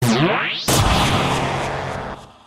Sound Effects
Gaster Blaster Sound